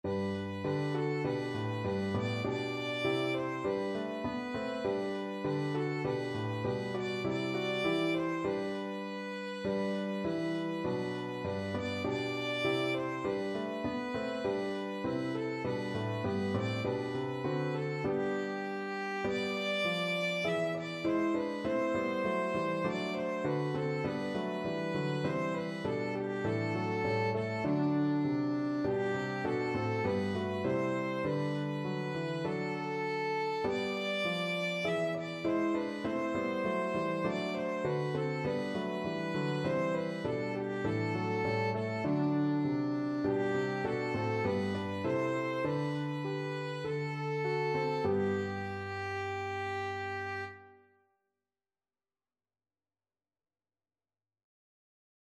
4/4 (View more 4/4 Music)
D5-E6